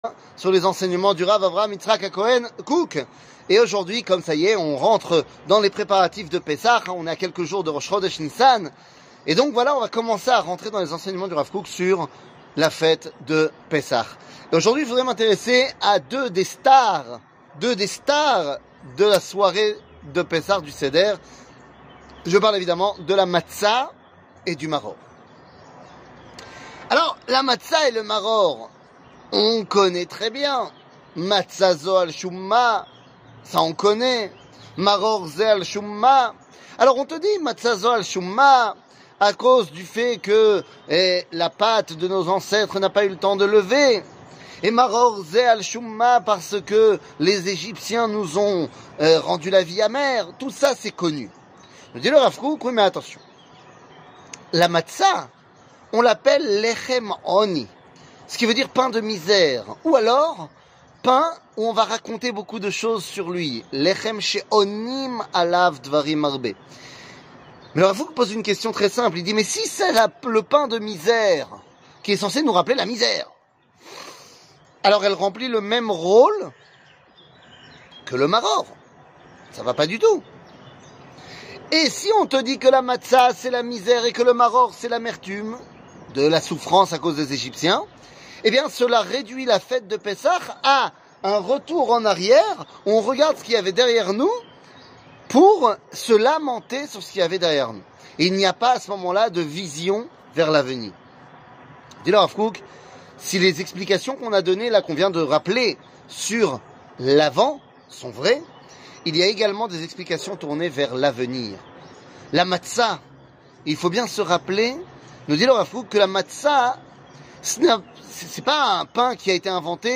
שיעורים קצרים